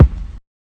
KICK GRITTY 3.wav